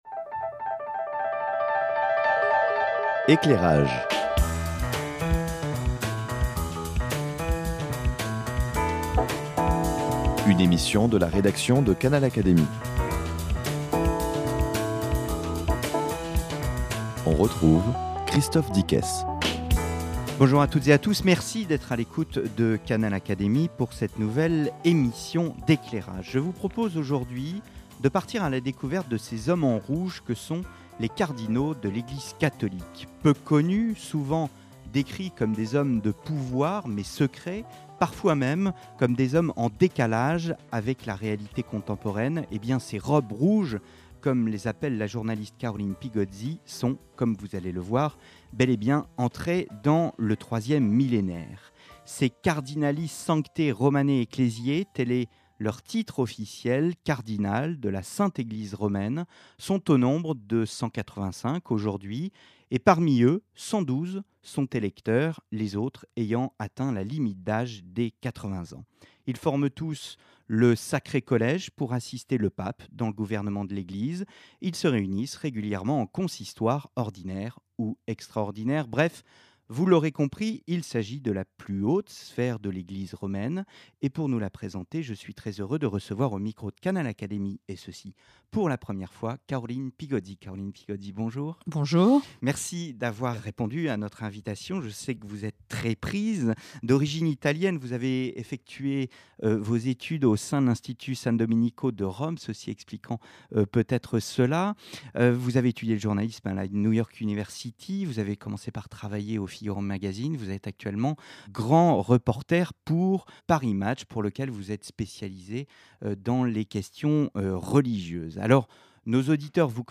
Les robes rouges, entretien avec vingt cardinaux de l’Eglise catholique